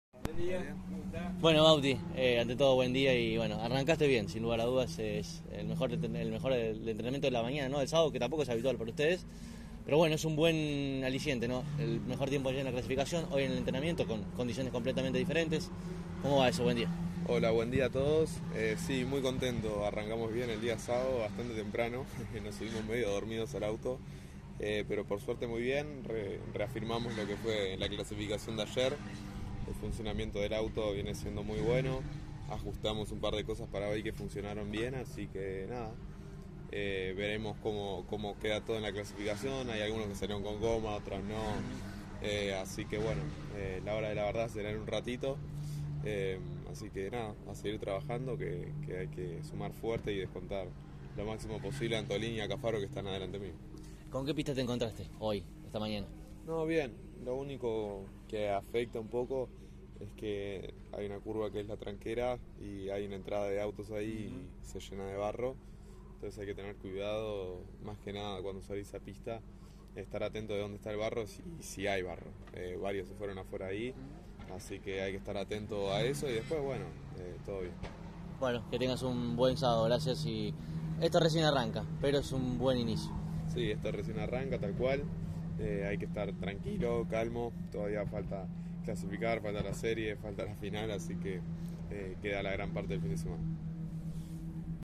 en diálogo exclusivo con CÓRDOBA COMPETICIÓN: